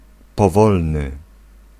Ääntäminen
IPA: traːx